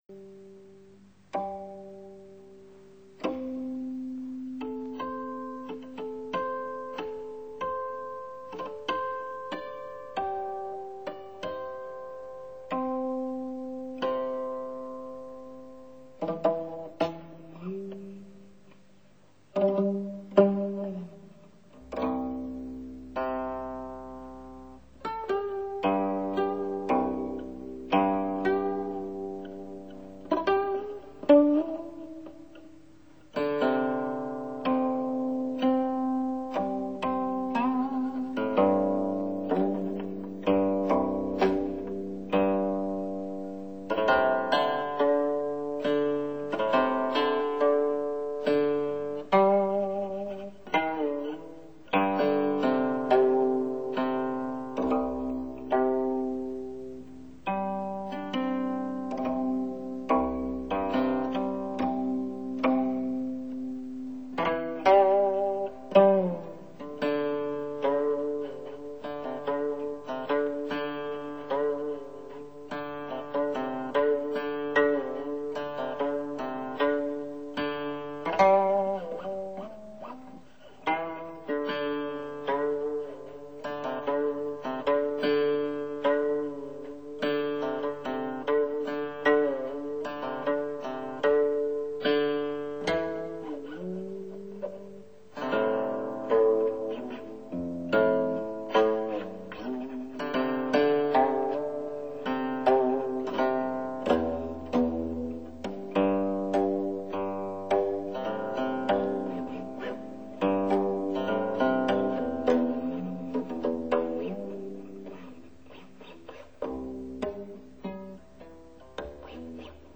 古曲